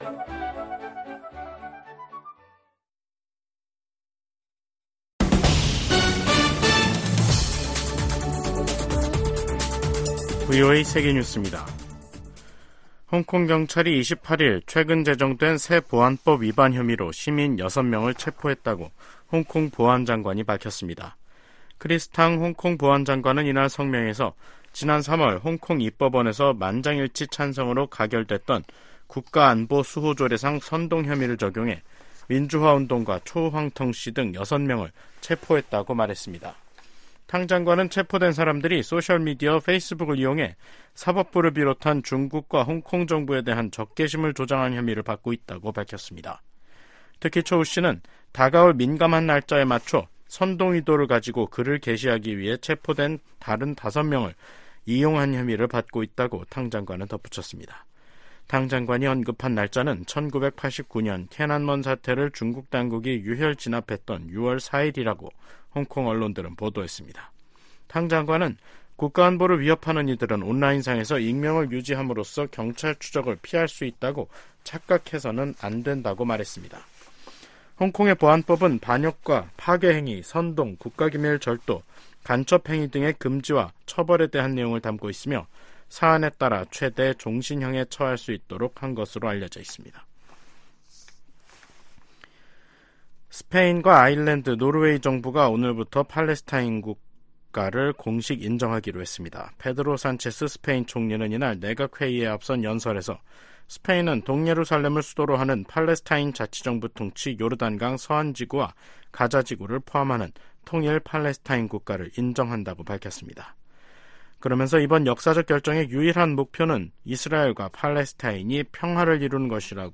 세계 뉴스와 함께 미국의 모든 것을 소개하는 '생방송 여기는 워싱턴입니다', 2024년 5월 28일 저녁 방송입니다. '지구촌 오늘'에서는 우크라이나가 러시아 본토를 공격할 권리가 있다고 유럽연합(EU) 최고위 외교 관리가 주장한 소식 전해드리고, '아메리카 나우'에서는 도널드 트럼프 전 대통령의 성추문 입막음 의혹 재판 최후변론이 28일에 진행될 이야기 살펴보겠습니다.